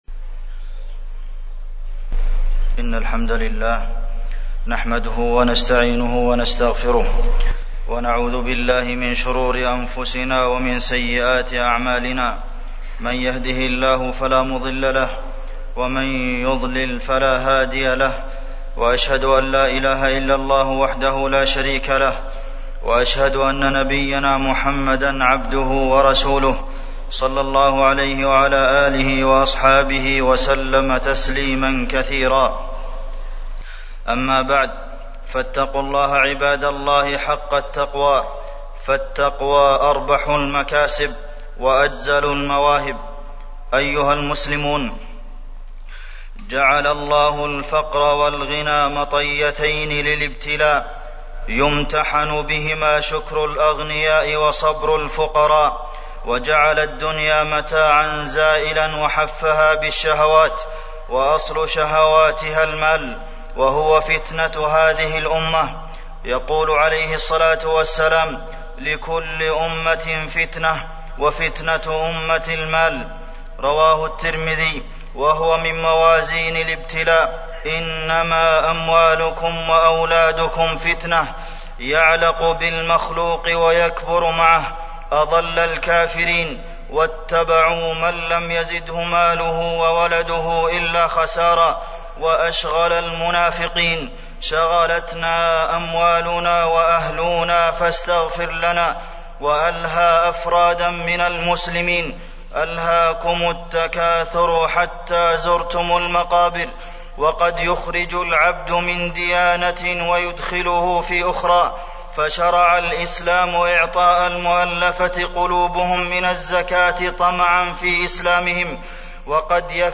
تاريخ النشر ١٠ صفر ١٤٢٧ هـ المكان: المسجد النبوي الشيخ: فضيلة الشيخ د. عبدالمحسن بن محمد القاسم فضيلة الشيخ د. عبدالمحسن بن محمد القاسم المال والكسب الحلال